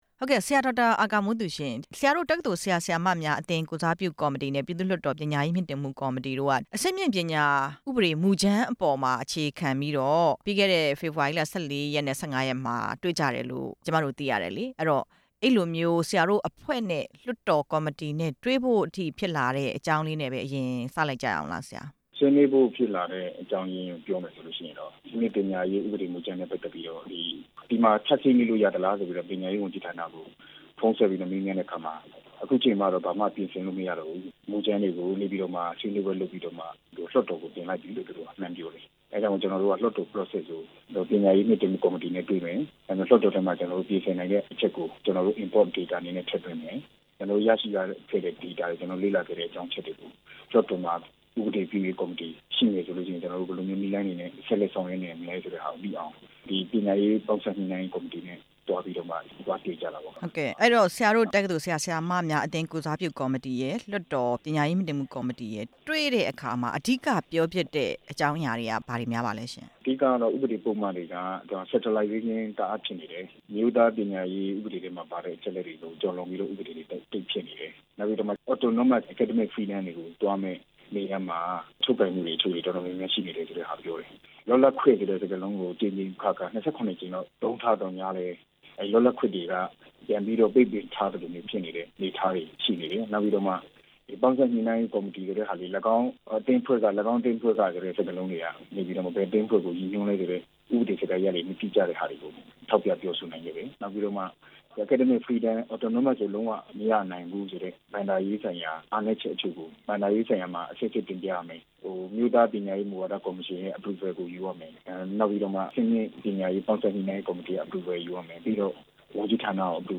အဆင့်မြင့် ပညာရေး ဥပဒေမူကြမ်းကိစ္စ ဆက်သွယ်မေးမြန်းချက်